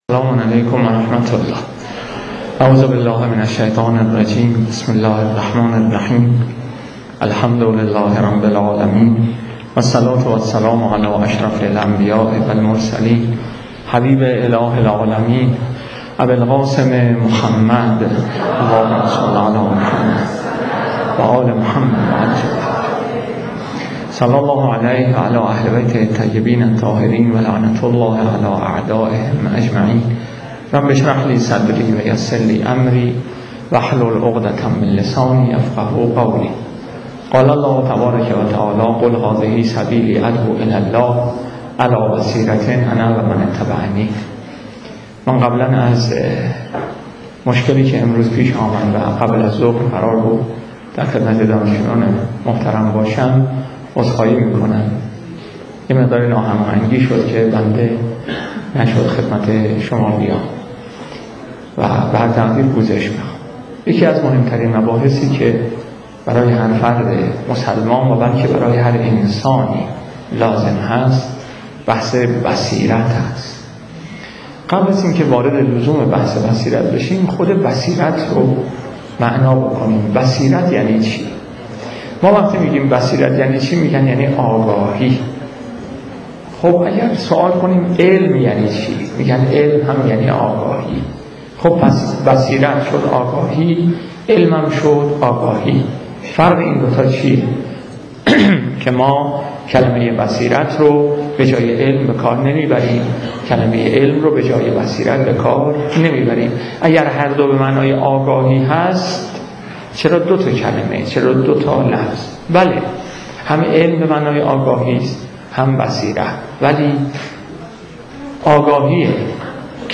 در بخش پایانی این سخنرانی شنیدنی، یکی از جوانان حاضر در سالن سخنرانی، در نهایت سادگی و کم اطلاعی یک سئوال ساده در باره سوریه و مقایسه آن با بحرین طرح می کند و با همین سئوال همه بافته های مهدی طائب به هم می ریزد.